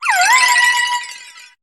Cri de Créhelf dans Pokémon HOME.